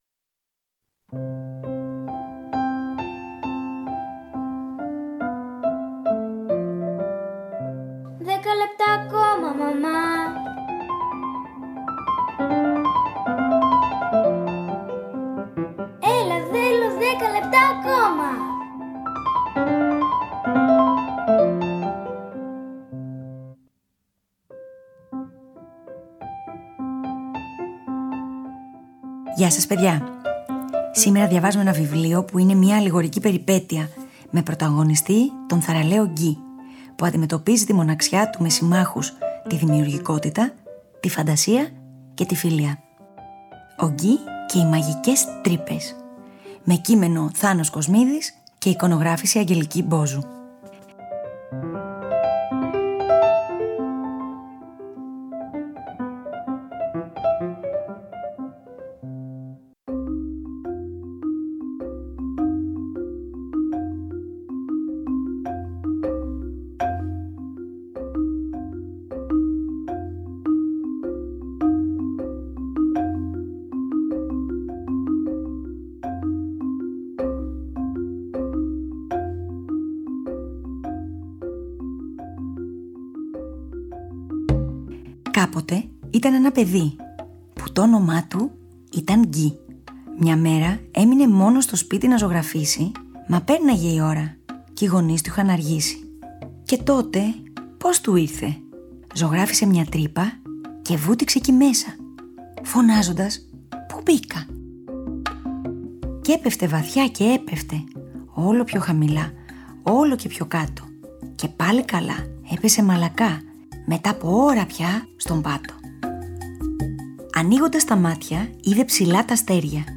Διαβάζουμε μια αλληγορική περιπέτεια με πρωταγωνιστή τον θαρραλέο Γκυ, που αντιμετωπίζει τη μοναξιά του με συμμάχους τη δημιουργικότητα, τη φαντασία και τη φιλία.